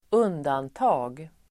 Uttal: [²'un:danta:g]